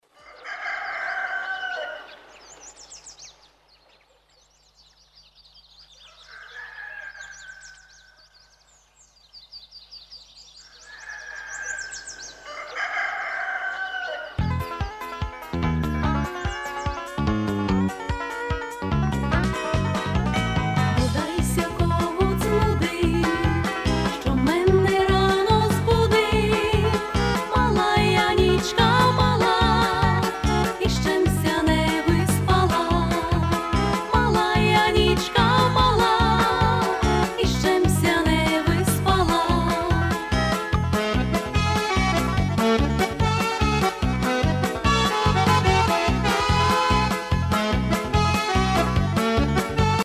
Collection of Folk Songs.